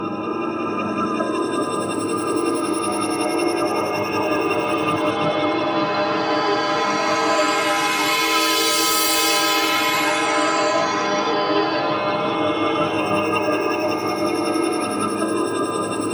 Index of /90_sSampleCDs/Spectrasonic Distorted Reality 2/Partition D/07 SCI-FI 1